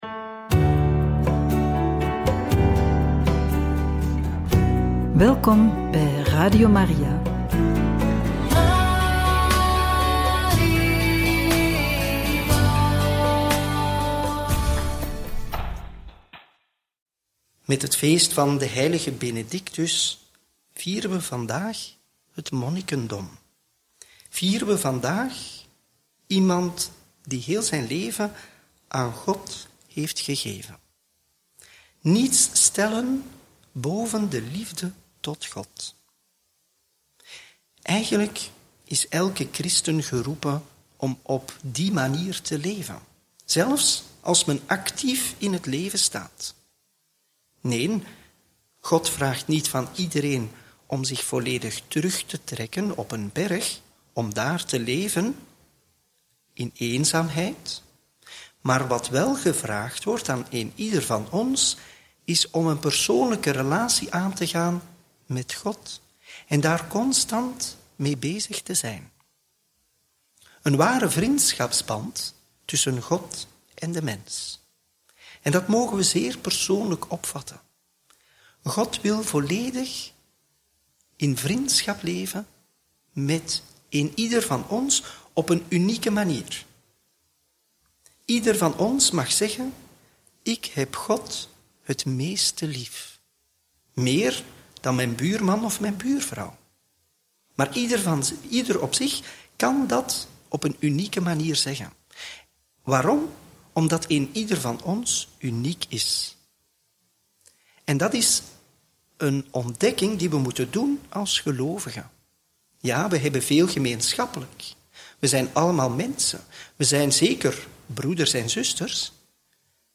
Homilie bij het Evangelie van vrijdag 11 juli 2025 – Mt 19, 27-29